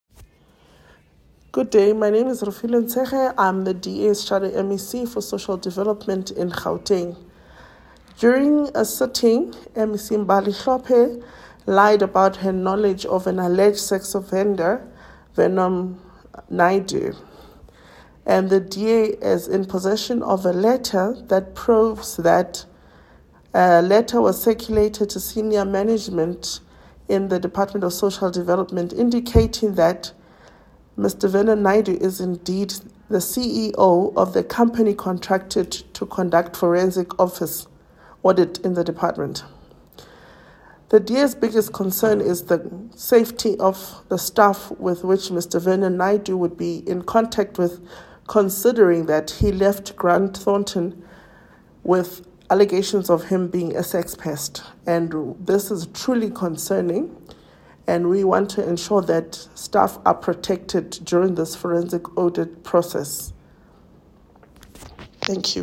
soundbite by Refiloe Nt’sekhe MPL